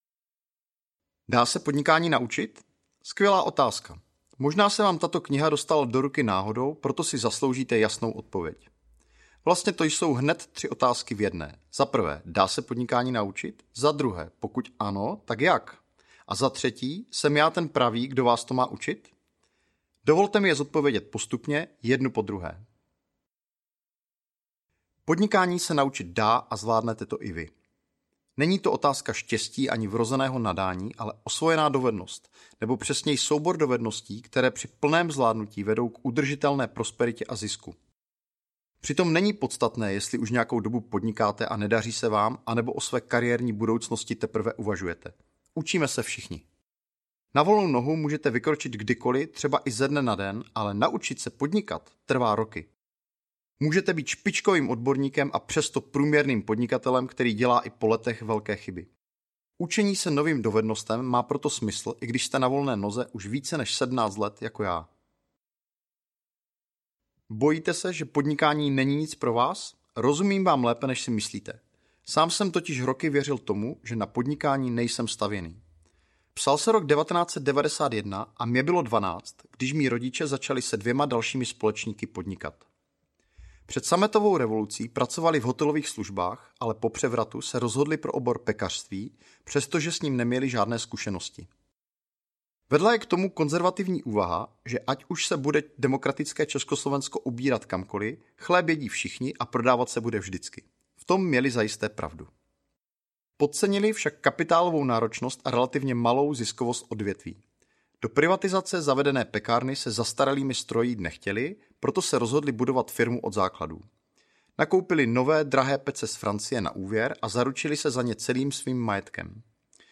Na volné noze audiokniha
Ukázka z knihy